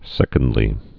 (sĕkənd-lē)